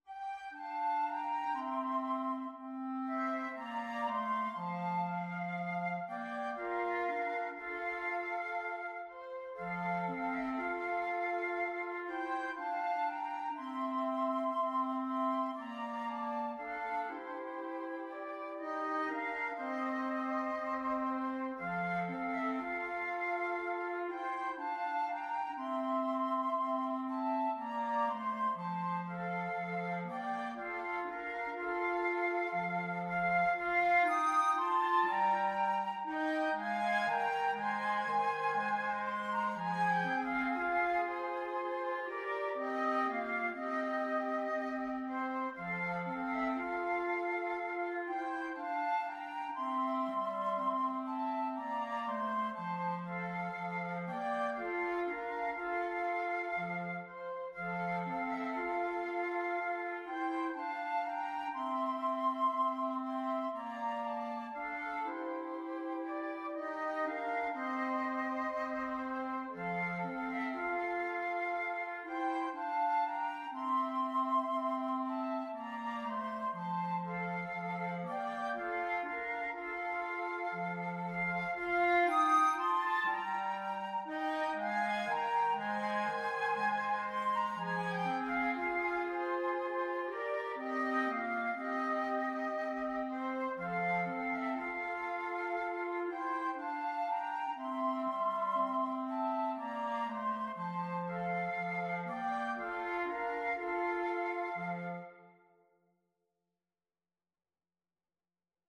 Flute 1Flute 2Clarinet
F major (Sounding Pitch) G major (Clarinet in Bb) (View more F major Music for 2-Flutes-Clarinet )
3/4 (View more 3/4 Music)
Slow, expressive =c.60